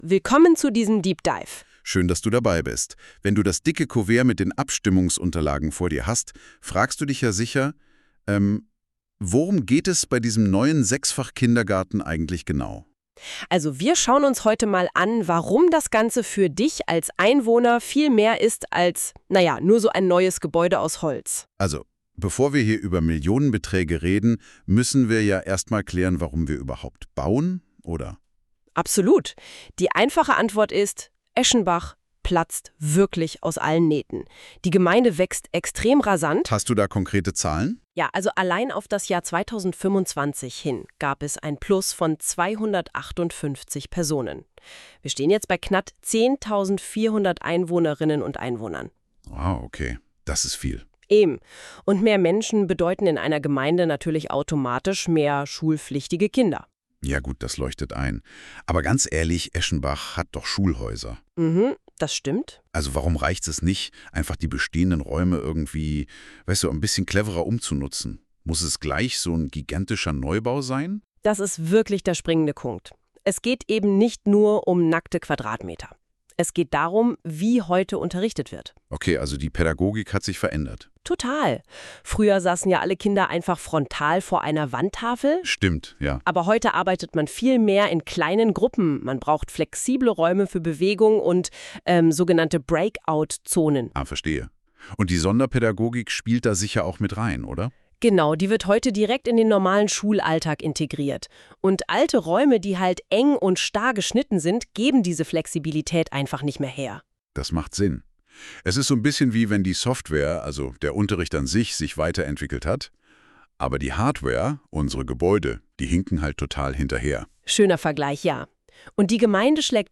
Diese ist mit KI generiert worden und fasst den wichtigsten Inhalt auf gut verständliche Art zusammen.